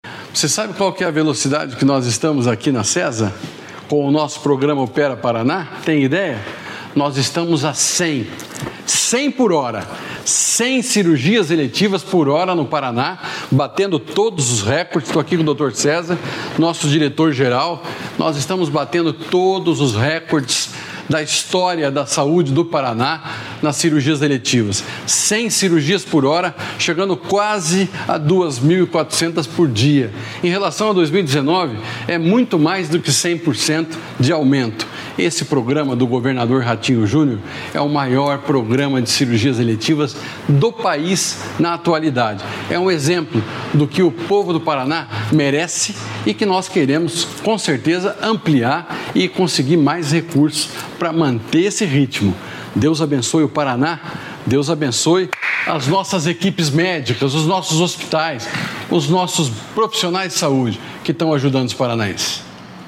Sonora do secretário da Saúde, Beto Preto, sobre o recorde de cirurgias eletivas no Estado | Governo do Estado do Paraná